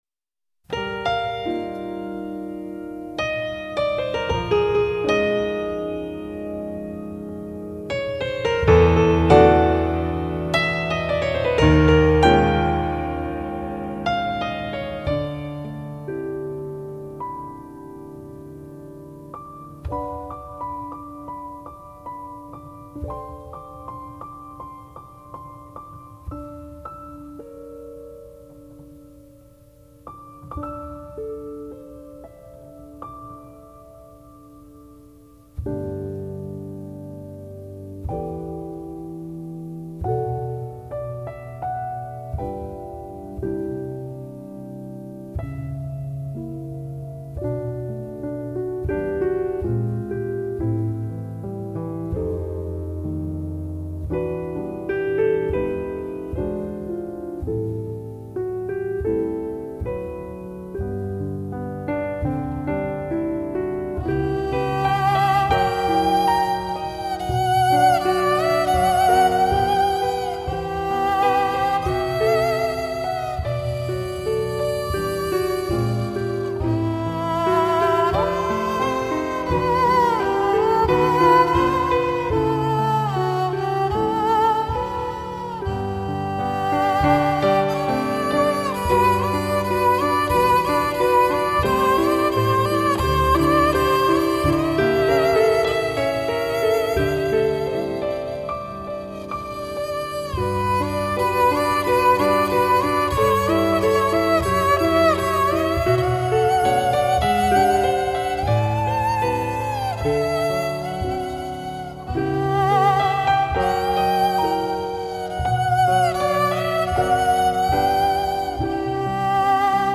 5.4MB 피아노연주곡
클래시컬한 분위기가 물씬 풍기는 연주곡입니다요.